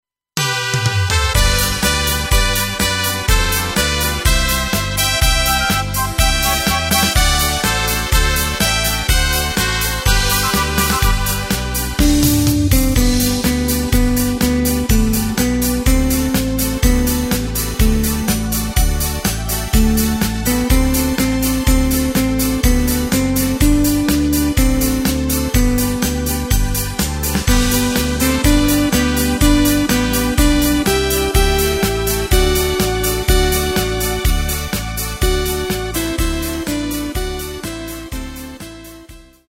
Takt:          2/4
Tempo:         124.00
Tonart:            G
Schlager-Polka aus dem Jahr 1996!